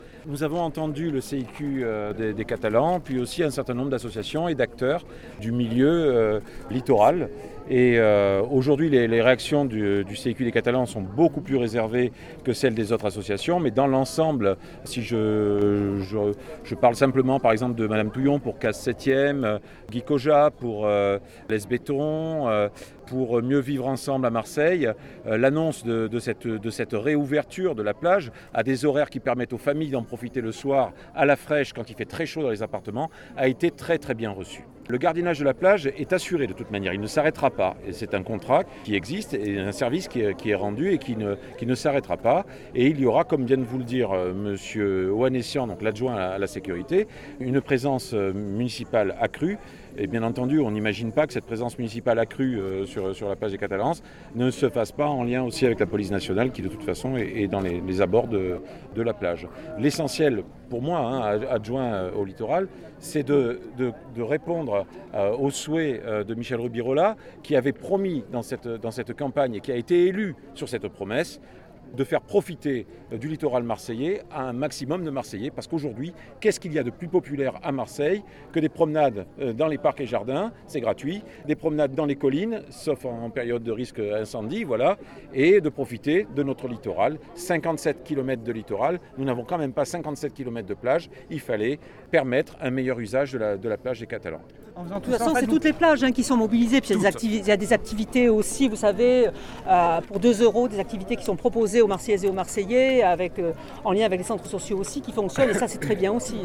Et cet été qui prend place du 1er août au 23 septembre 2020 vient d’être lancé ce 28 juillet par la maire de Marseille Michèle Rubirola accompagnée par nombre de ses adjoints et un public venu en nombre pour saluer l’initiative…